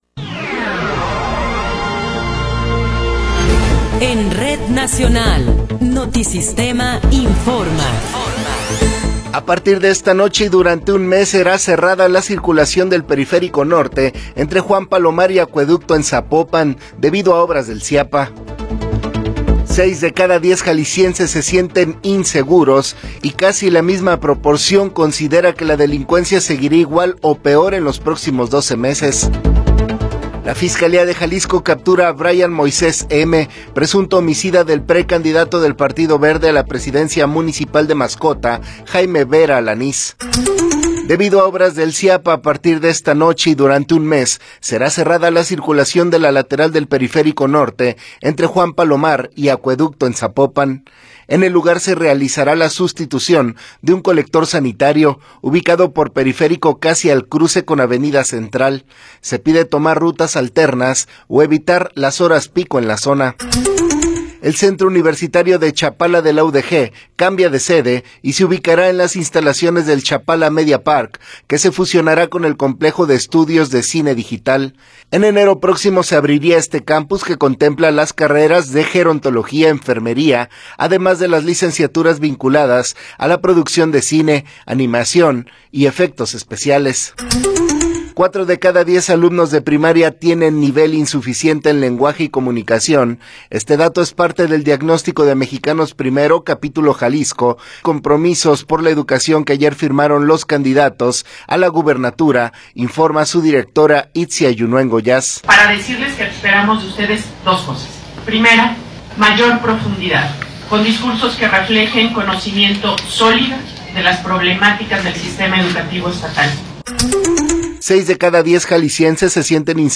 Noticiero 9 hrs. – 10 de Abril de 2024
Resumen informativo Notisistema, la mejor y más completa información cada hora en la hora.